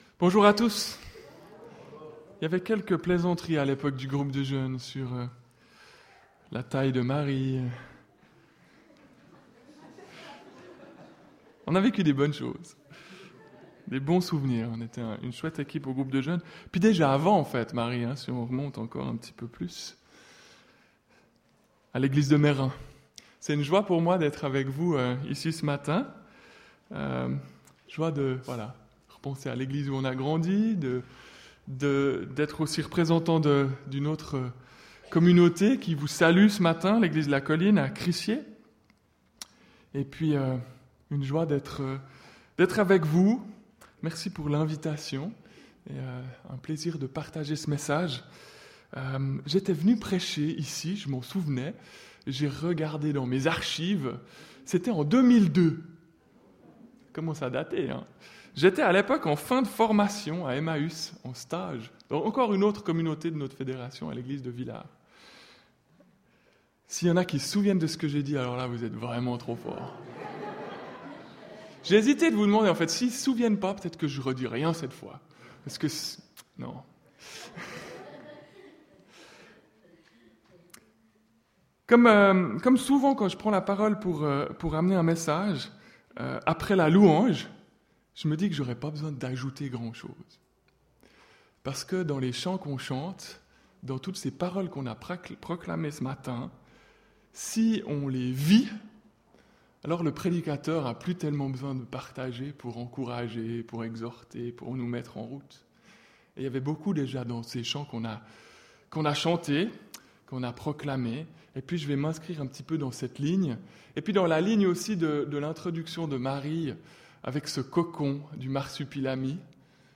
Culte du 7 juin 2015